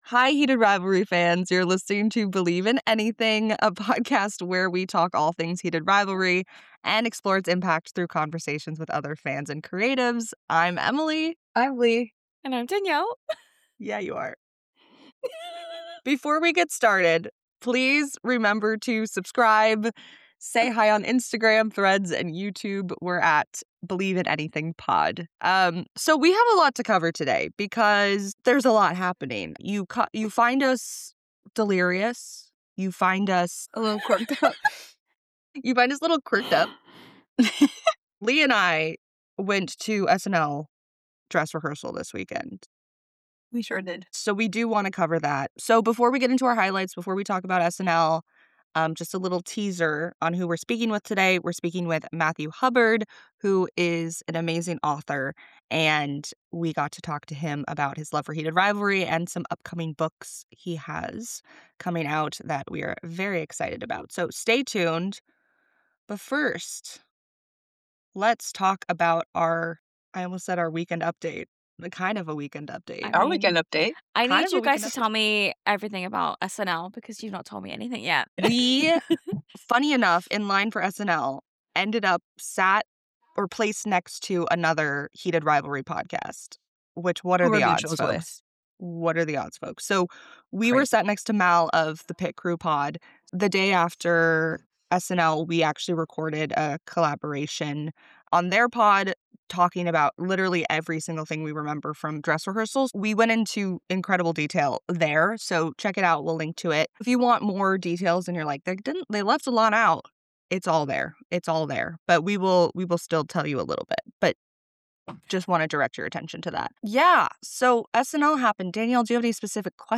(We were very emotional this week)